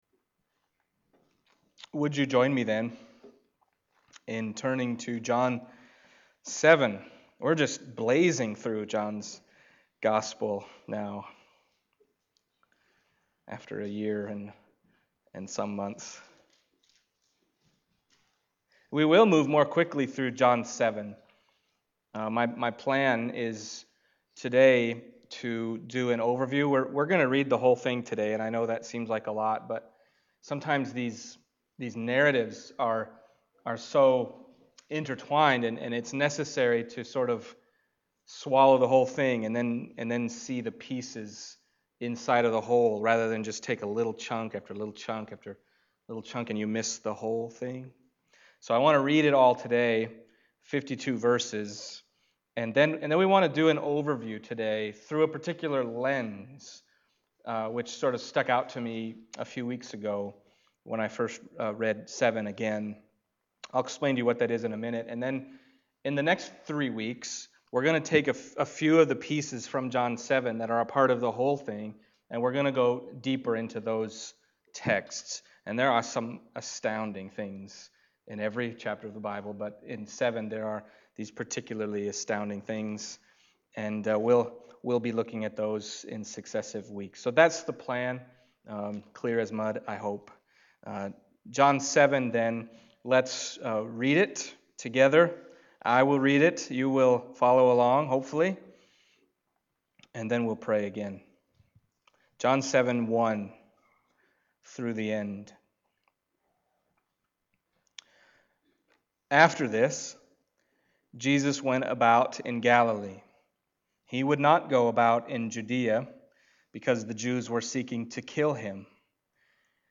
John Passage: John 7:1-52 Service Type: Sunday Morning John 7:1-52 « Christ